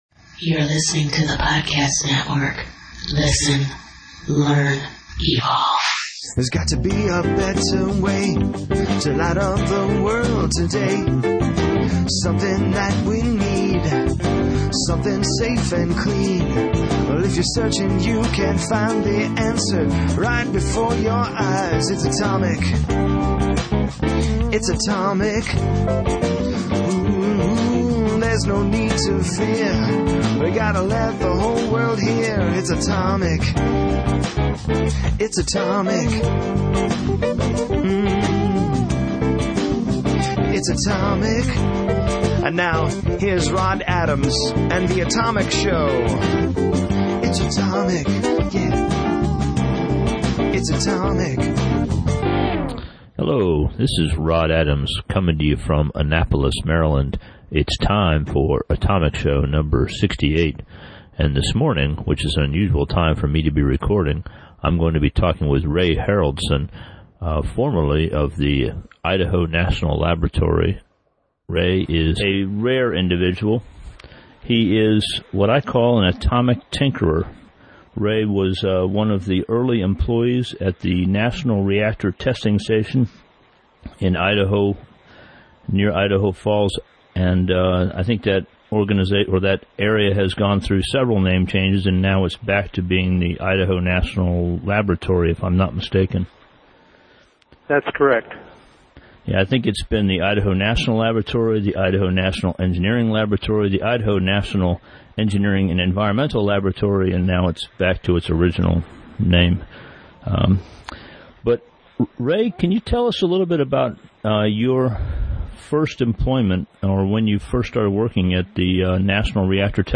A good friend of mine contacted me recently and told me that I needed to interview